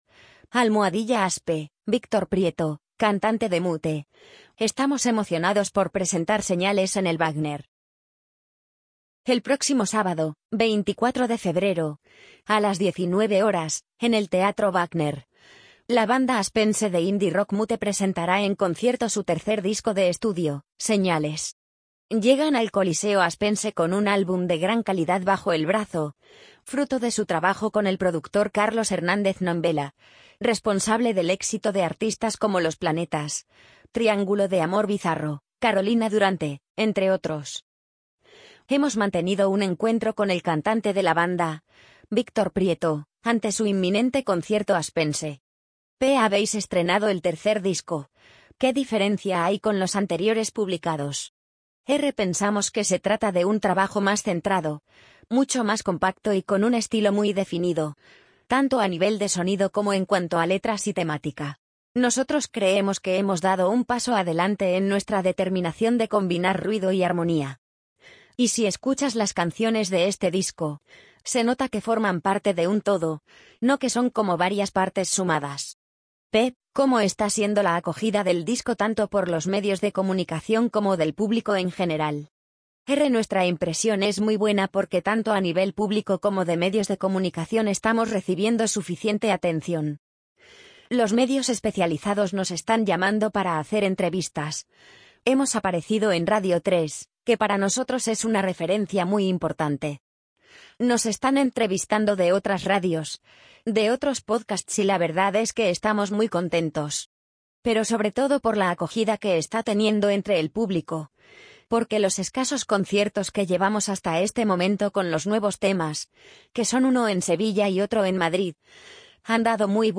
amazon_polly_71170.mp3